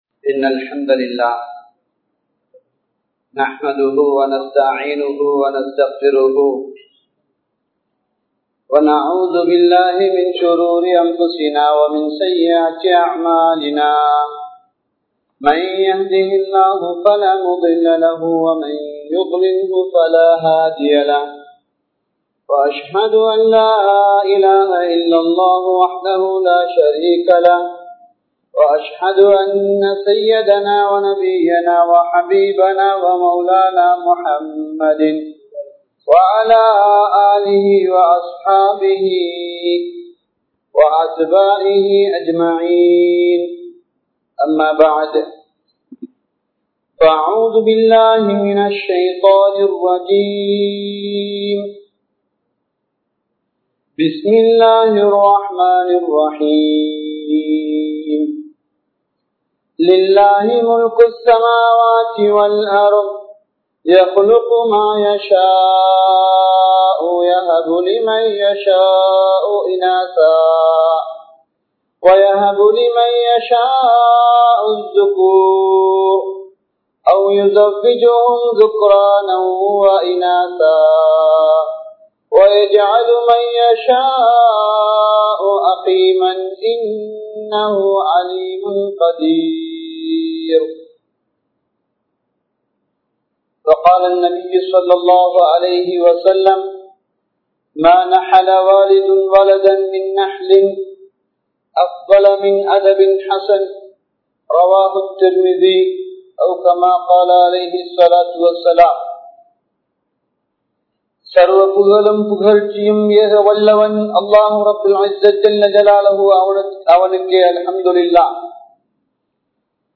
Pettroarhal Ematkku Kidaiththa Oru Arutkodai (பெற்றோர்கள் எமக்கு கிடைத்த ஒரு அருட்கொடை) | Audio Bayans | All Ceylon Muslim Youth Community | Addalaichenai